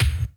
JB KICK 1.wav